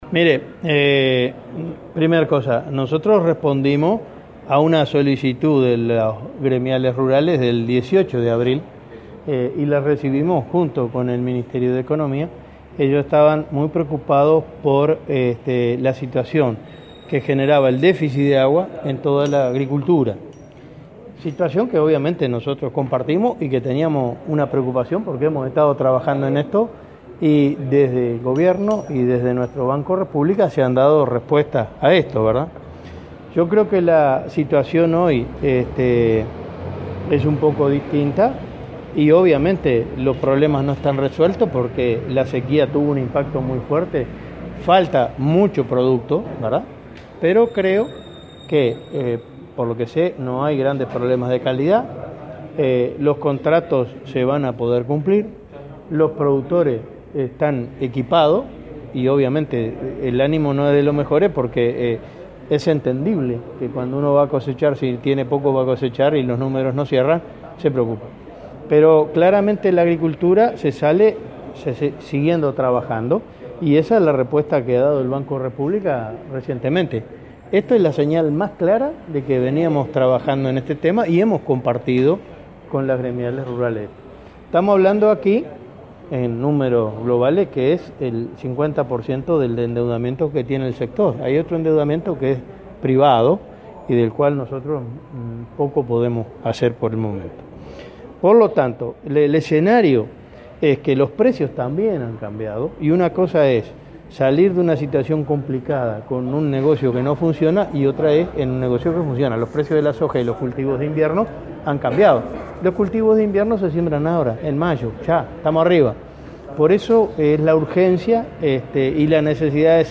“Desde el Gobierno se ha dado respuesta, es la señal más clara de que estamos trabajando en aliviar la situación de productores agropecuarios”, subrayó el ministro Enzo Benech tras reunirse junto a su par Danilo Astori con representantes de gremiales rurales. Dijo que falta producto por la sequía, pero no hay grandes problemas de calidad y los contratos se cumplirán.